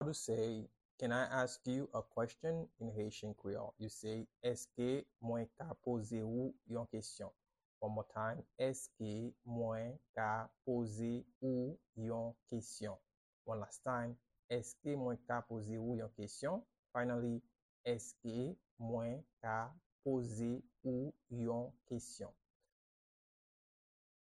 Can-I-ask-you-a-question-in-Haitian-Creole-–-Eske-mwen-ka-poze-ou-yon-kesyon-pronunciation-by-a-Haitian-teacher-1.mp3